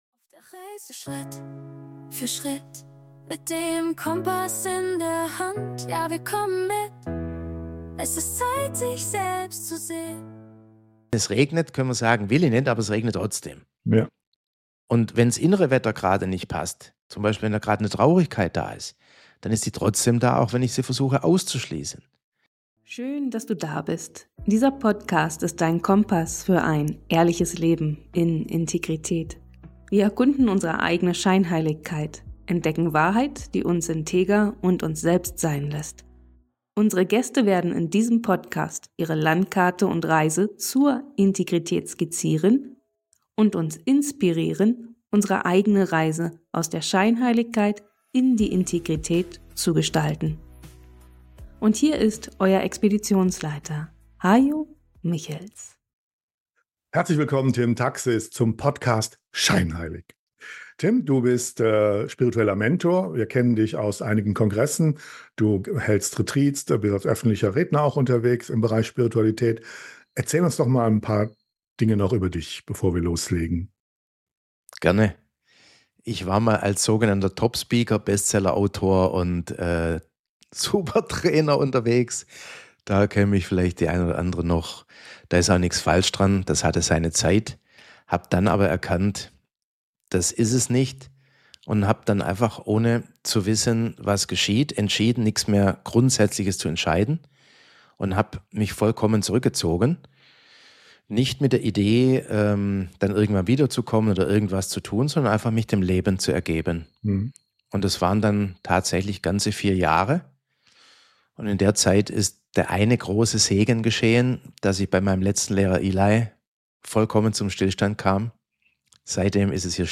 Ein inspirierender Dialog, der Mut macht, innezuhalten und die Illusionen von Trennung und Mangel hinter sich zu lassen.